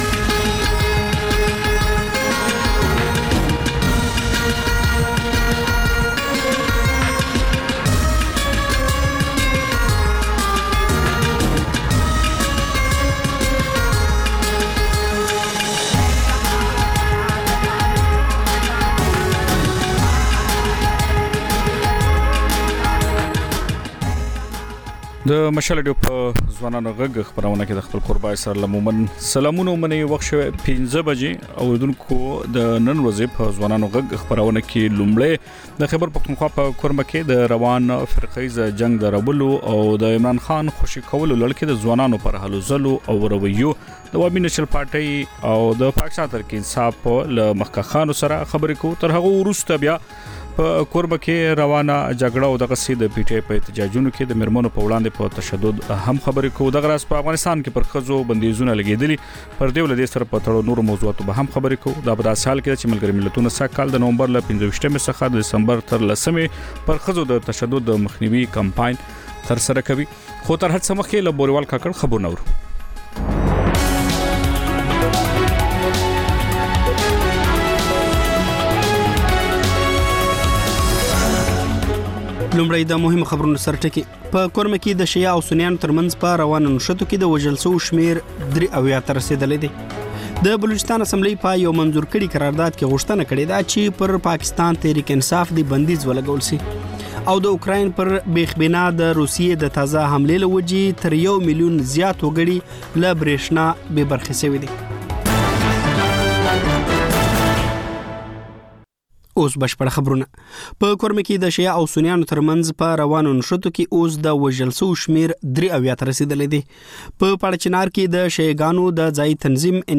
د مشال راډیو ماښامنۍ خپرونه. د خپرونې پیل له خبرونو کېږي، بیا ورپسې رپورټونه خپرېږي.
ځینې ورځې دا ماښامنۍ خپرونه مو یوې ژوندۍ اوونیزې خپرونې ته ځانګړې کړې وي چې تر خبرونو سمدستي وروسته خپرېږي.